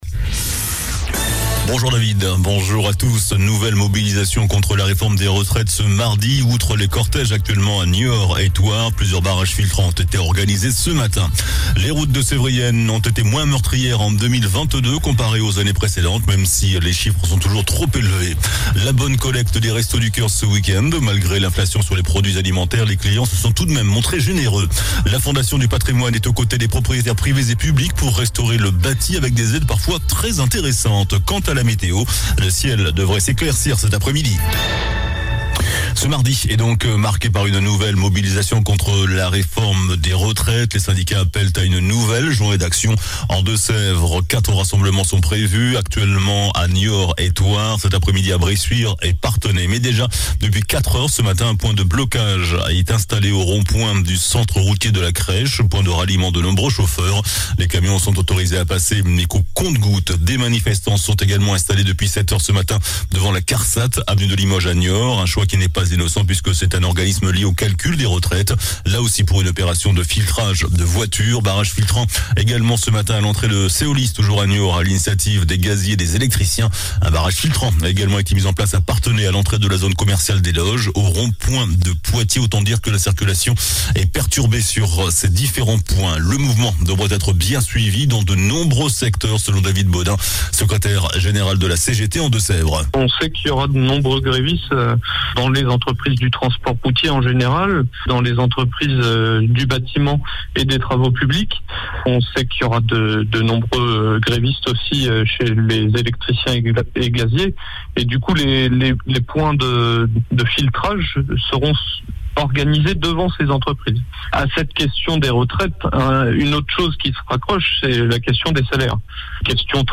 JOURNAL DU MARDI 07 MARS ( MIDI )